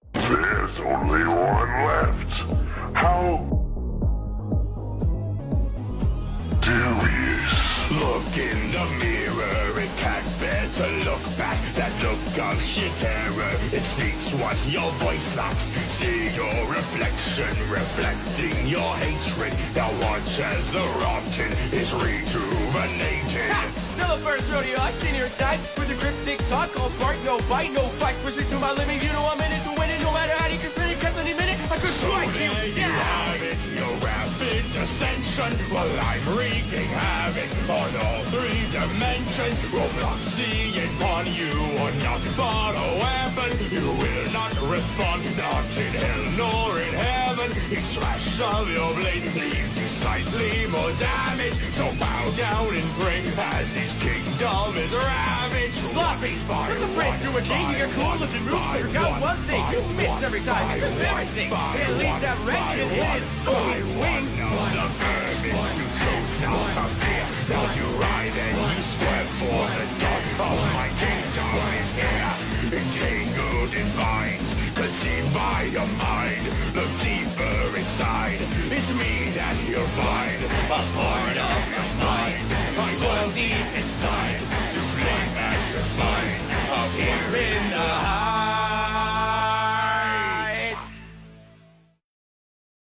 (со словами)